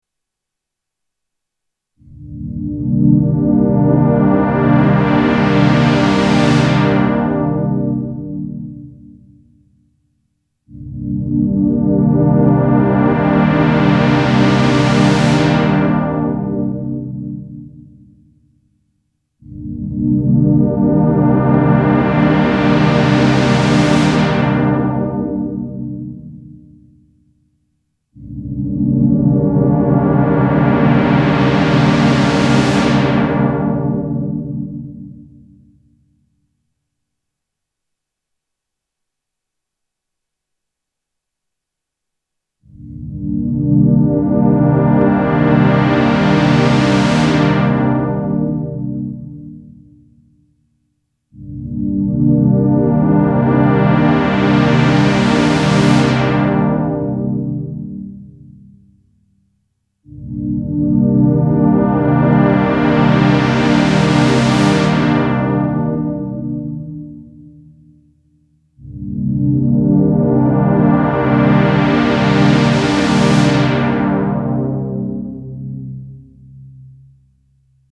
Poly Demo es sind 8 nacheinander zu hören 2x4 erst der alte Slop: 0 -> auf 11Uhr -> auf 2 Uhr -> Max ---> ab 12 Uhr wird der recht unbrauchbar und klingt verstimmt! dann Vintage 0 -> auf 11Uhr -> auf 2 Uhr -> Max